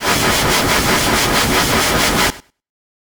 fami_fire.ogg